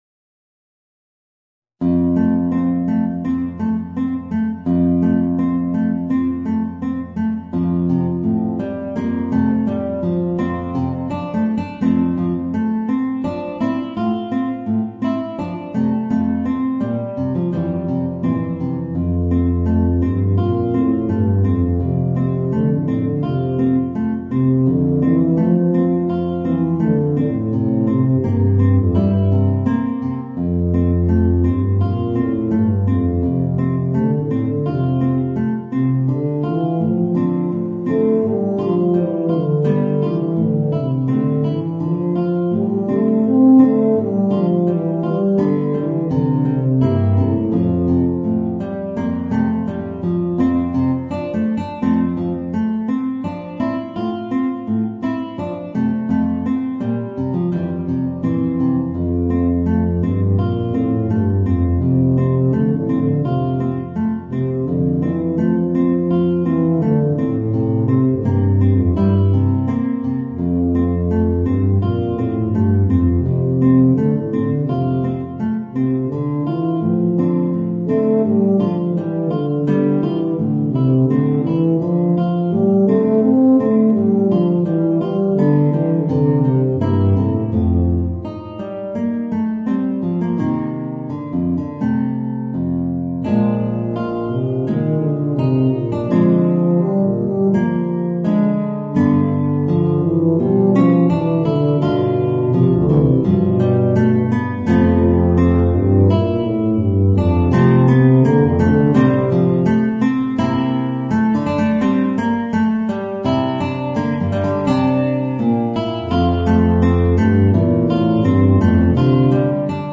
Voicing: Guitar and Eb Bass